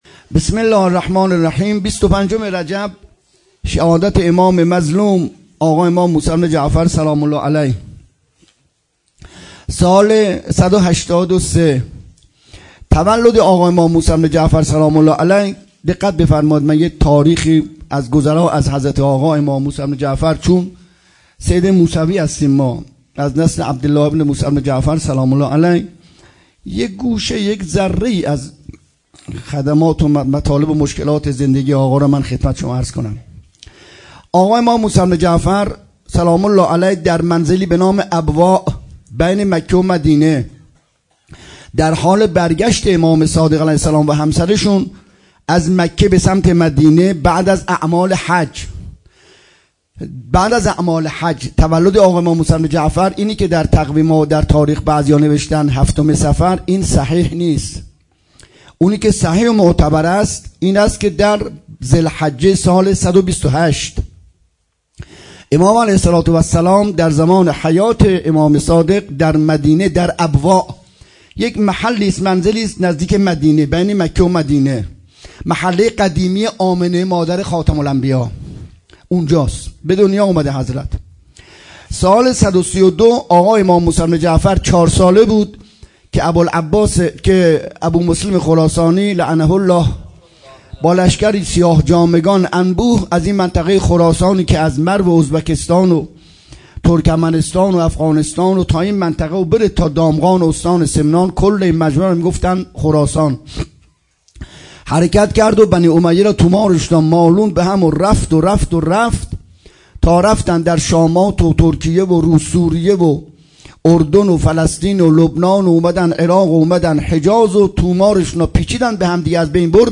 23 فروردین 97 - هیئت محبان الائمه - سخنرانی